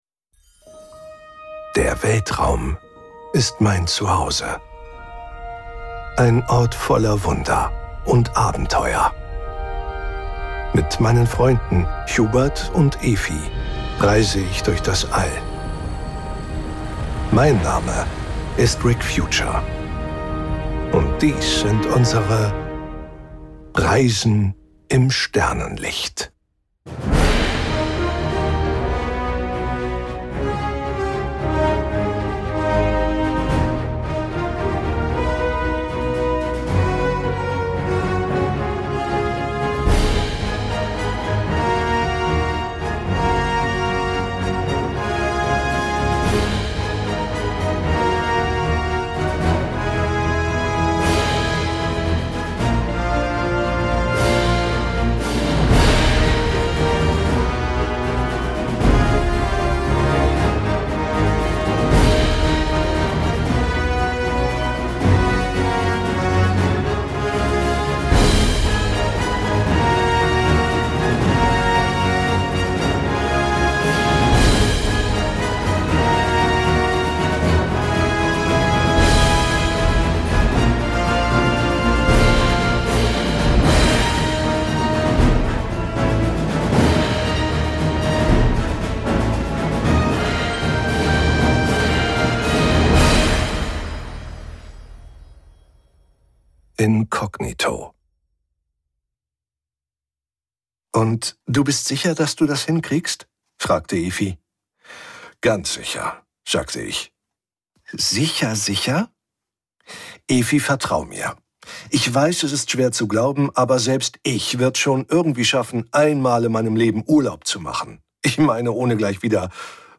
Die sechste Kurzgeschichte "Inkognito" aus der Sammlung "Reisen im Sternenlicht".